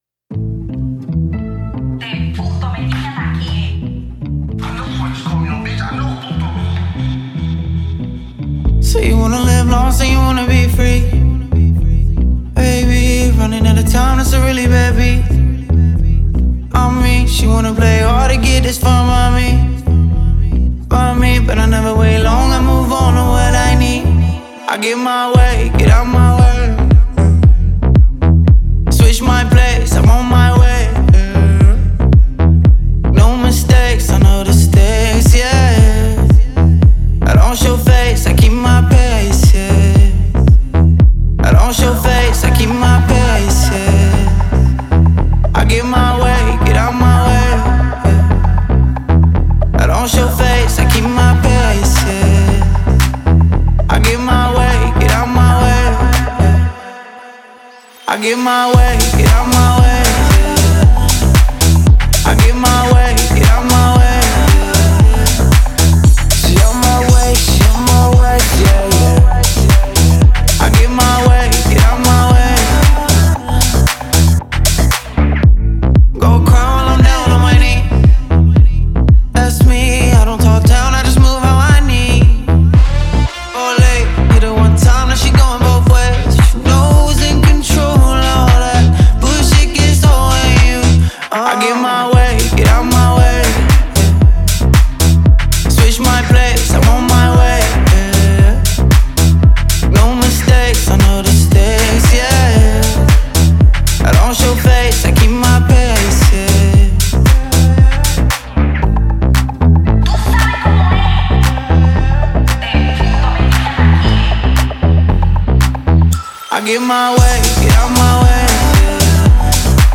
ЗарубежнаяDance